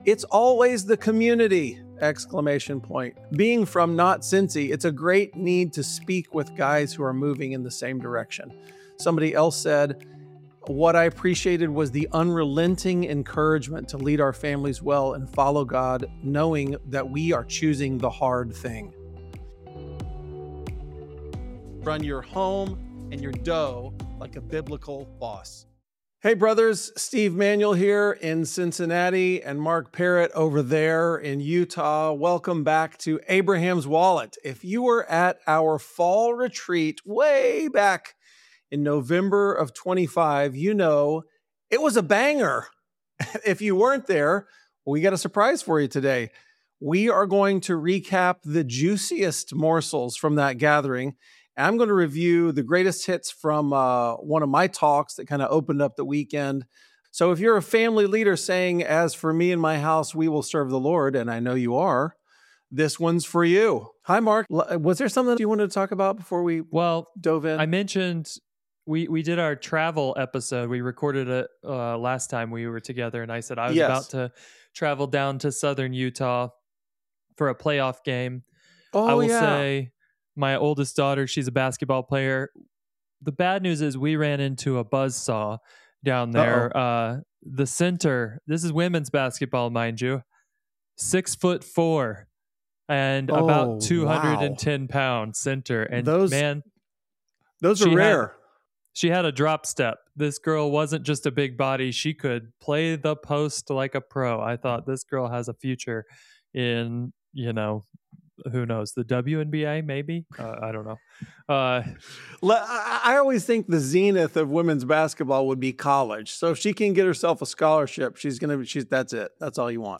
In this episode, we’re sharing the greatest hits — the standout ideas, the most impactful moments, and the messages that set the tone for the weekend. You’ll hear highlights from the opening talk and key insights that left many men challenged, encouraged, and ready to lead their homes with greater clarity.